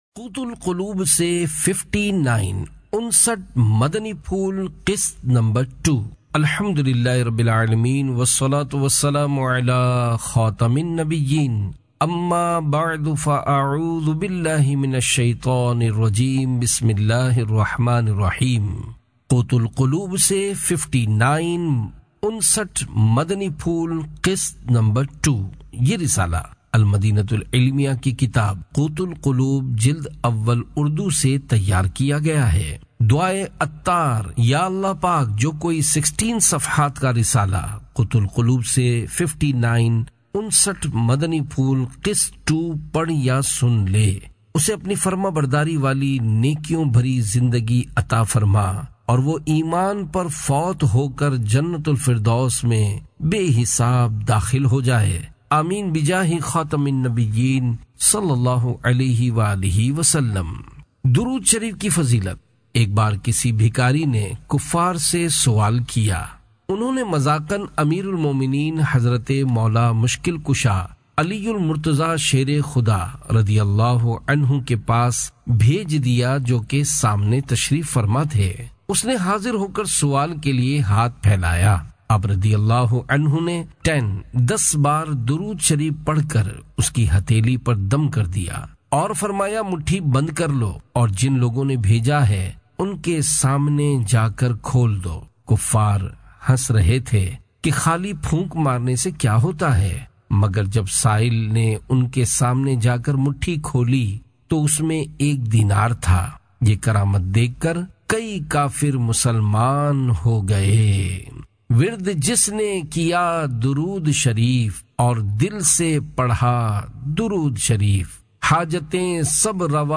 Audiobook - Qut Al Qulub se 59 Madani Phool Qist 2 (Urdu)